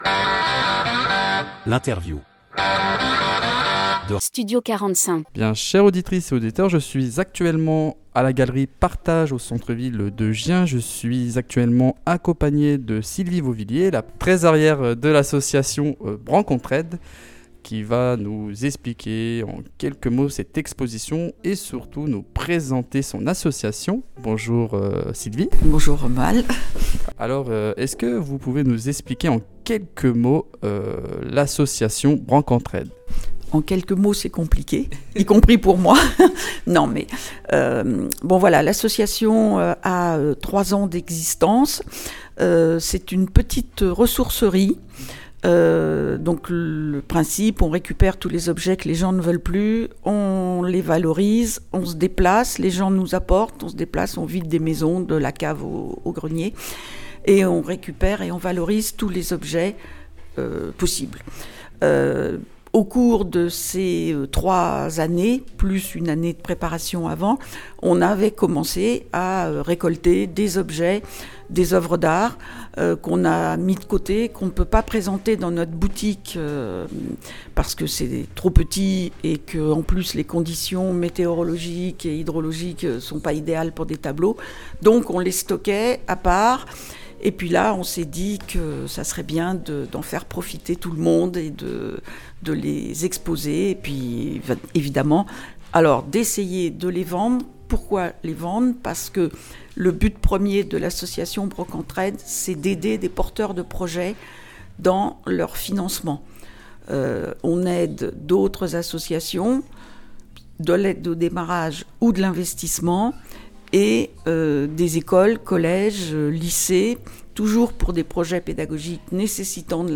Nouvelle interview sur Studio 45 !